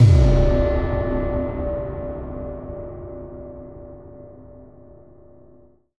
Synth Impact 23.wav